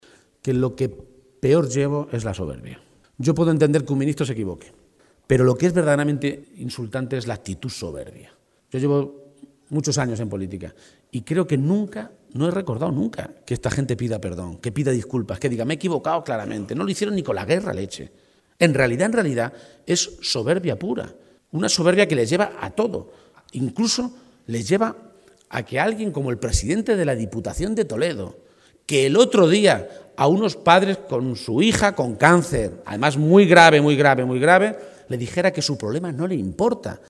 “No podemos tolerar que eso quede políticamente impune”, ha afirmado durante un acto de campaña en la localidad de Marchamalo (Guadalajara), en la que ha sido necesario cambiar de ubicación ante la masiva afluencia de militantes y simpatizantes socialistas.